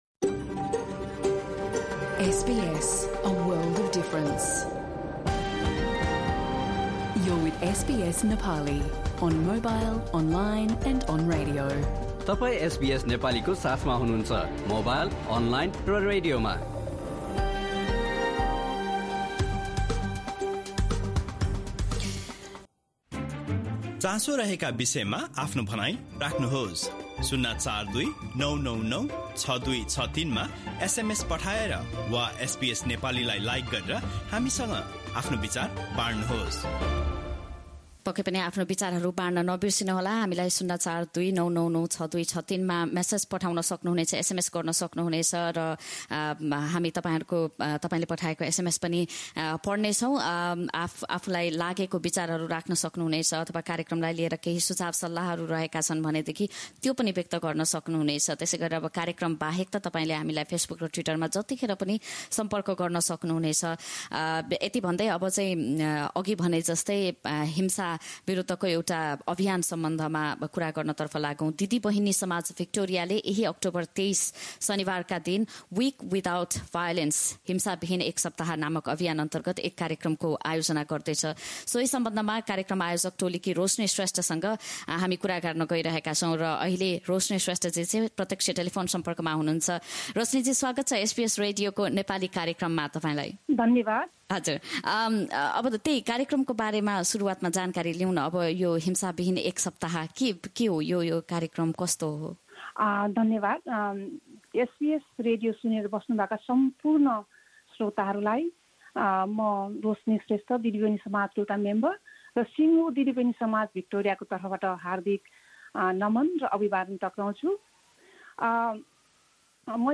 कुराकानी।